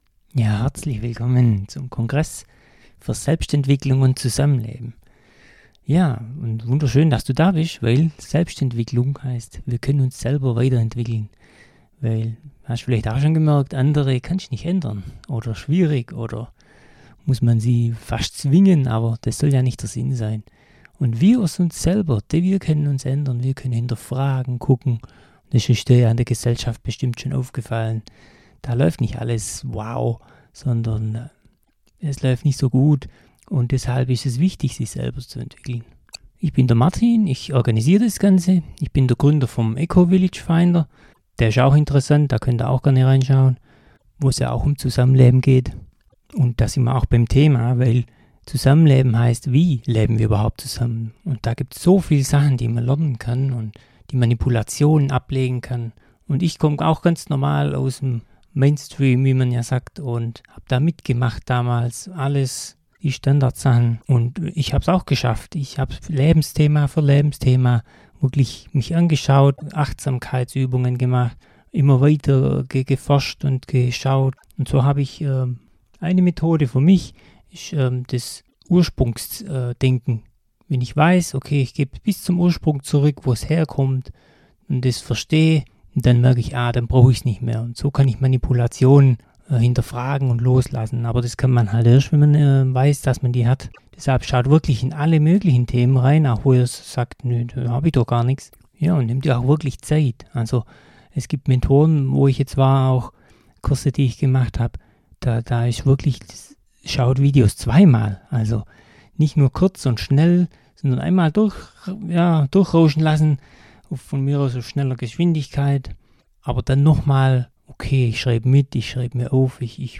Hier meine Sprachnachricht an dich: